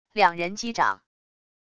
两人击掌wav音频